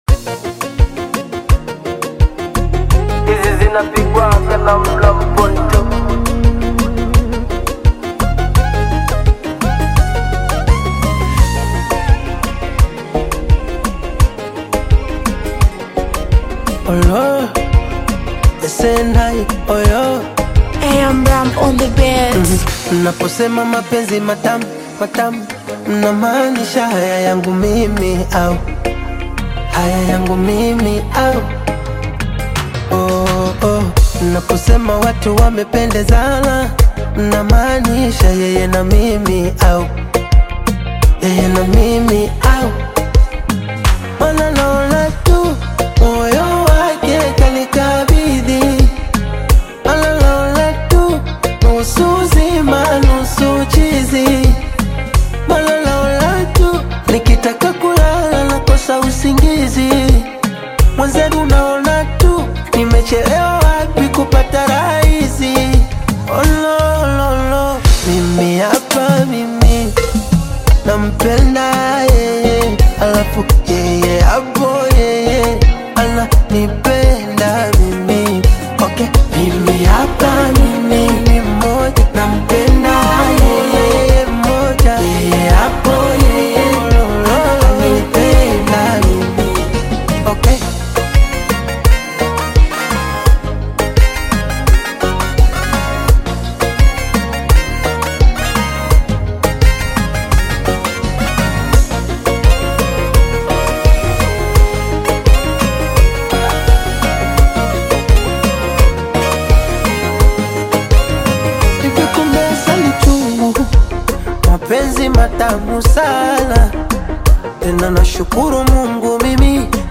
Tanzanian Bongo Flava artist, singer, and songwriter
Bongo Flava